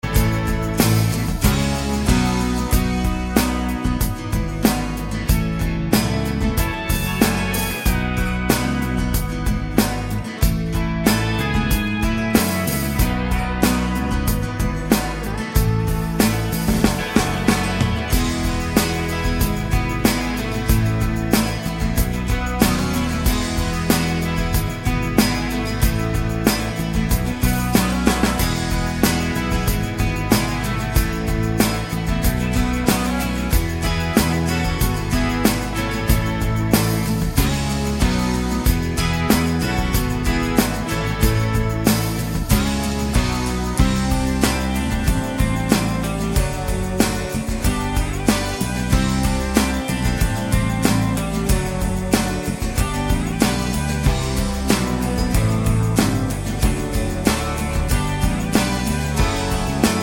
no Backing Vocals Country (Female) 3:37 Buy £1.50